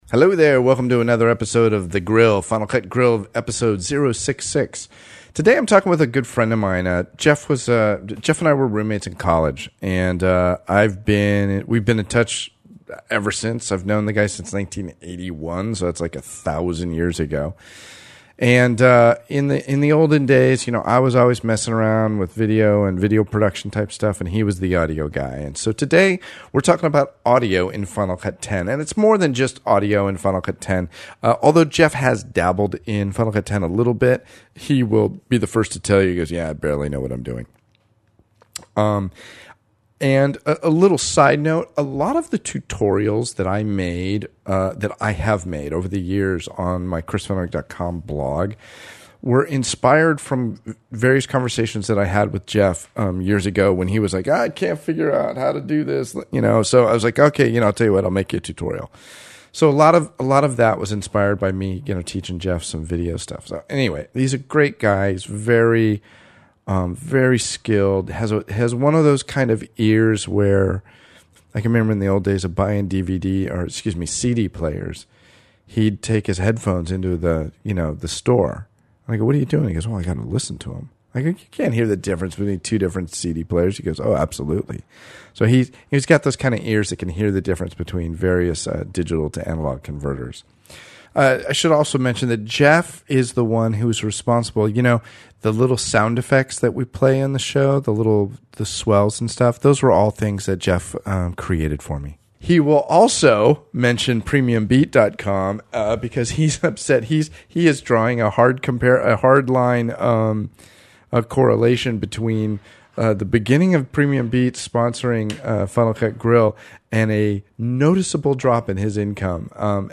This is a great episode if you want to hear two old college buddies talk about 35 years in the industry and FCPX.